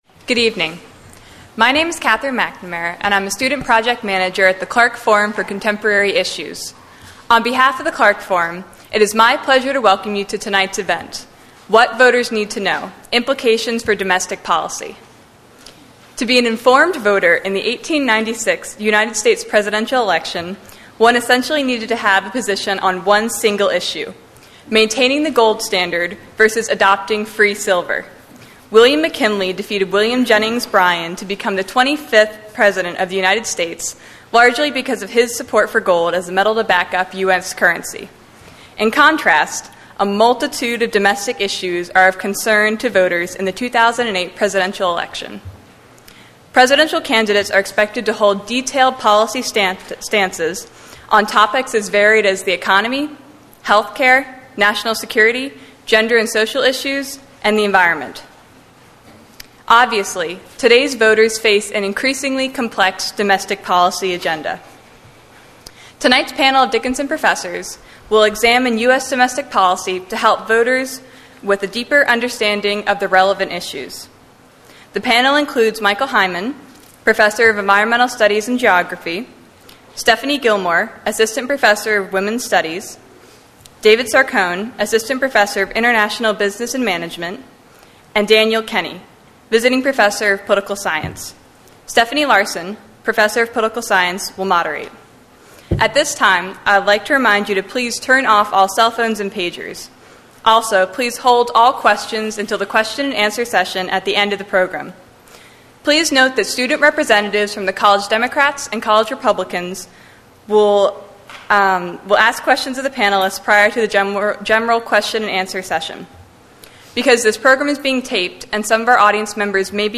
Panel discussion with Dickinson faculty held on 10/30/08.